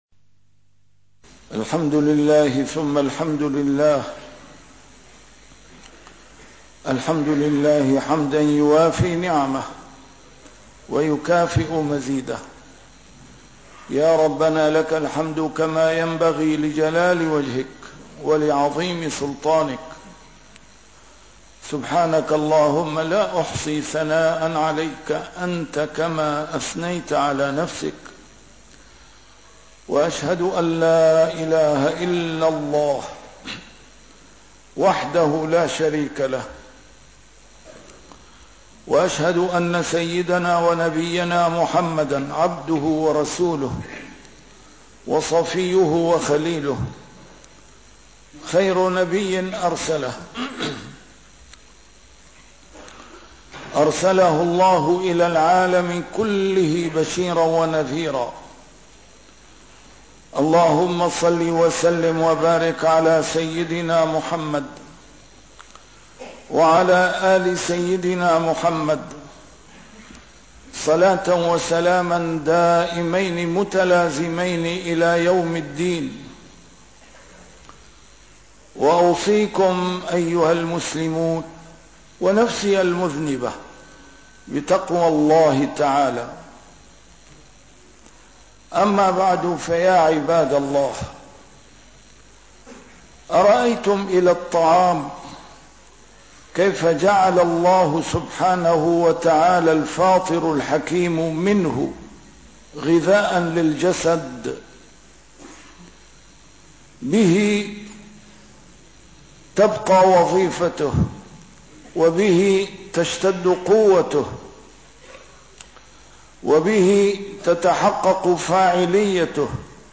A MARTYR SCHOLAR: IMAM MUHAMMAD SAEED RAMADAN AL-BOUTI - الخطب - الصلاة .. الصلاة .. الصلاة ..